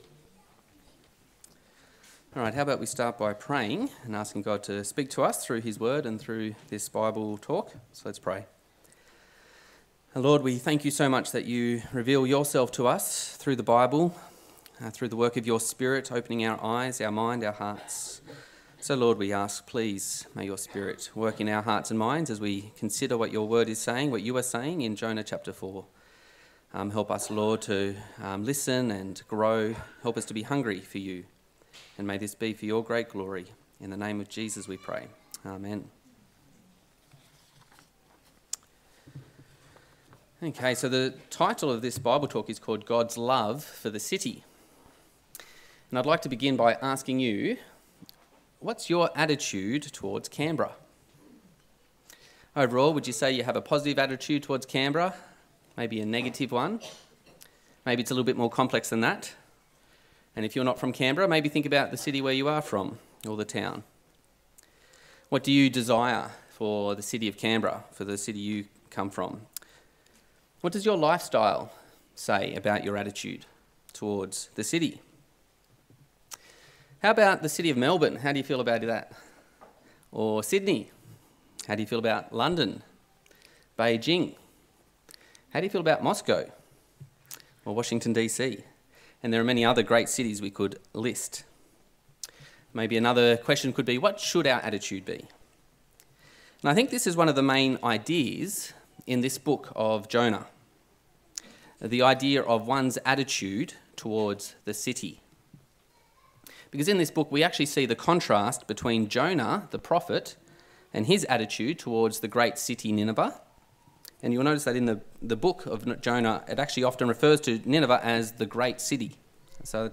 A sermon on the book of Jonah 4
Service Type: Sunday Service